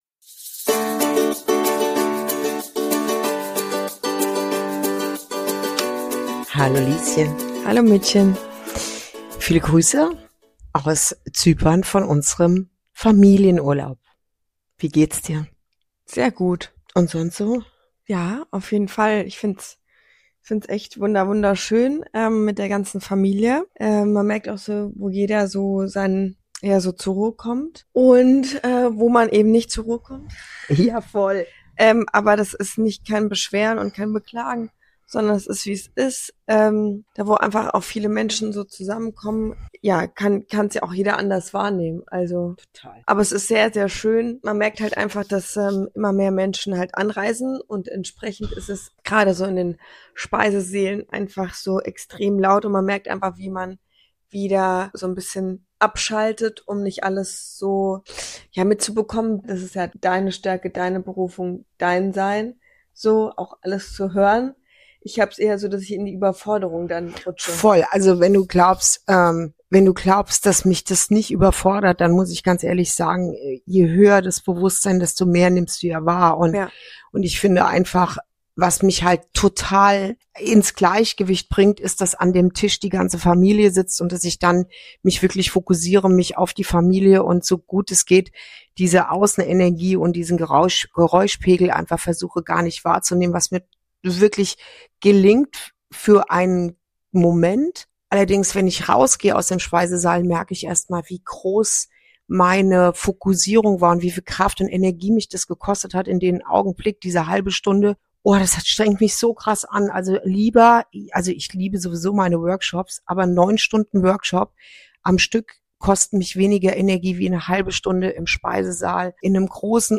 Ein Gespräch über Generationen, Grenzen, Verbundenheit – und die große Kunst, sich selbst und andere sein zu lassen.